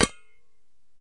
Household Items Etc. » Stainless Steel Thermos
描述：Hitting a stainless steel thermos with scissors ]:>
标签： VacuumFlask Empty StainlessSteel SteelThermos Bright High Metal Stainless Jug Hit Thermos Steel
声道立体声